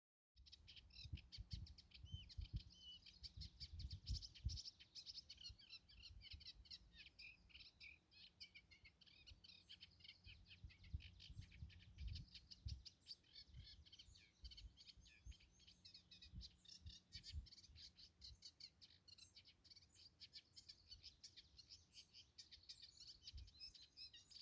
тростниковая камышевка, Acrocephalus scirpaceus
Administratīvā teritorijaRīga
СтатусСлышен голос, крики